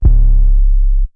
808s
808 (Zay 4).wav